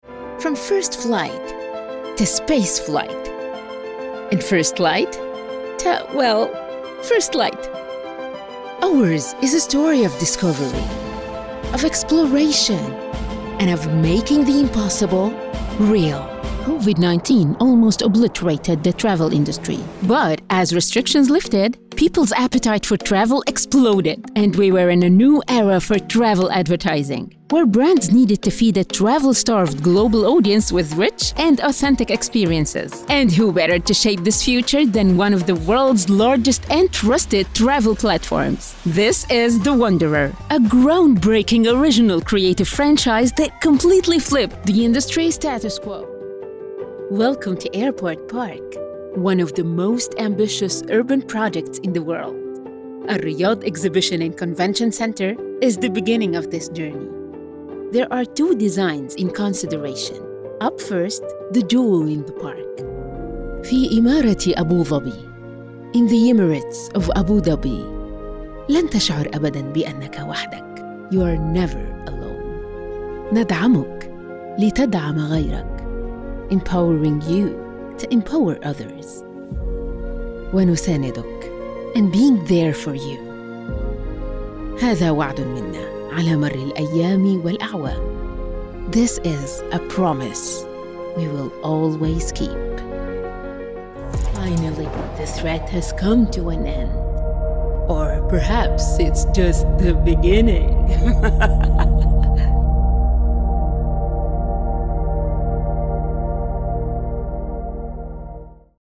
Female
Adult (30-50), Older Sound (50+)
Narration-Explanatory-Authentic
Middle Eastern-Gen Am English
Words that describe my voice are Warm, Conversational.
1106English-Bilingual-Middle_Eastern-1_Arabic_line.mp3